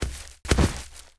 minf_drop2.wav